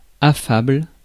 Ääntäminen
UK : IPA : /ˈæf.ə.bəl/